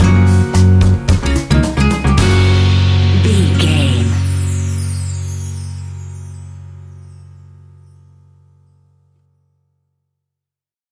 An exotic and colorful piece of Espanic and Latin music.
Ionian/Major
F#
romantic
maracas
percussion spanish guitar